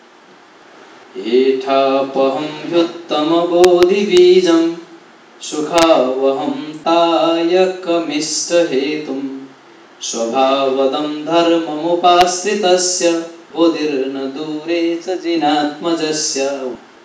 upajāti
msa17_31_sing_1st.wav